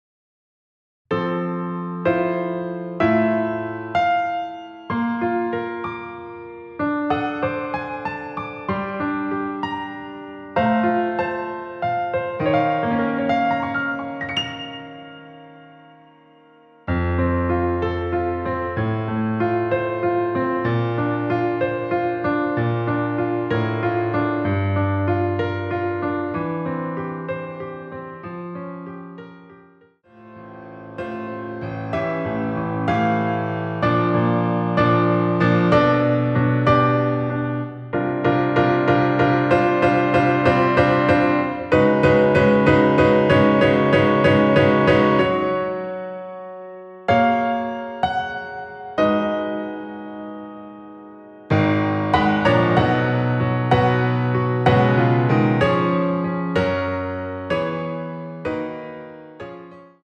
반주가 피아노 하나만으로 되어 있습니다.(아래의 유튜브 동영상 참조)
원키에서(+6)올린 피아노 버전 MR입니다.
앞부분30초, 뒷부분30초씩 편집해서 올려 드리고 있습니다.